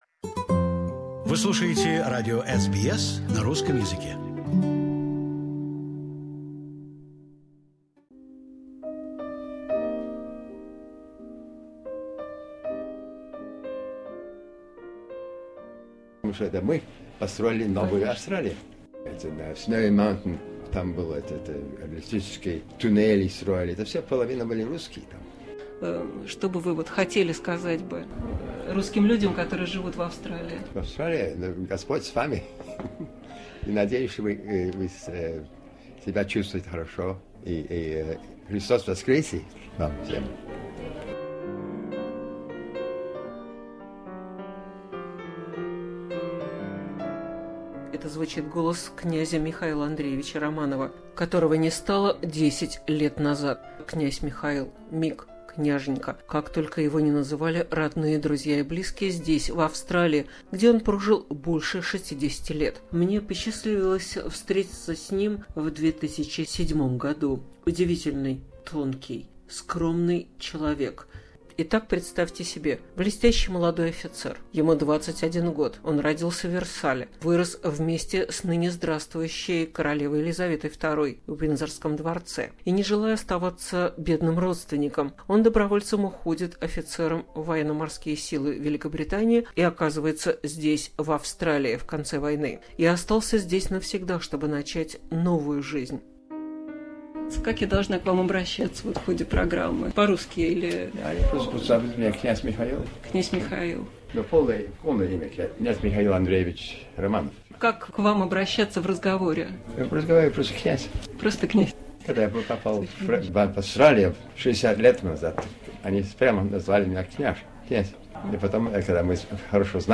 As the Easter approaches we have restored a unique interview from our archive.